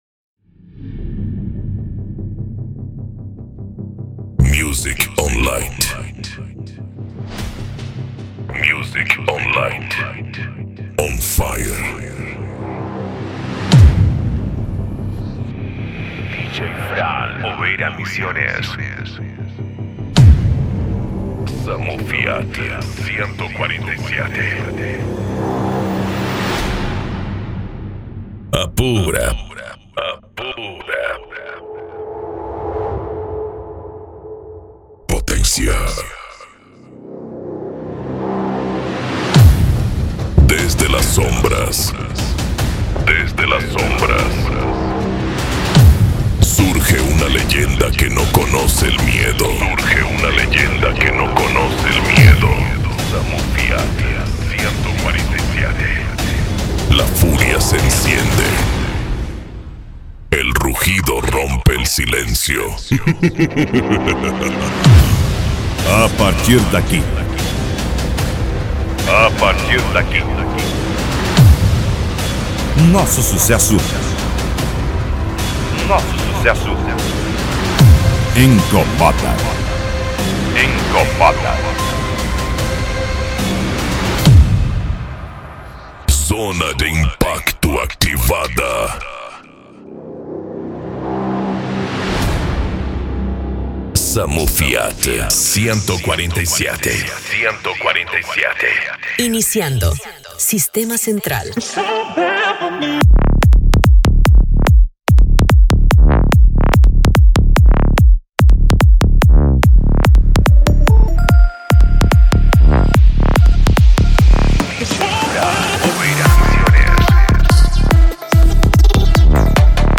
PANCADÃO
Remix